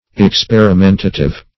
Search Result for " experimentative" : The Collaborative International Dictionary of English v.0.48: Experimentative \Ex*per`i*men"ta*tive\, a. Experimental; of the nature of experiment.